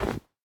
snow1.ogg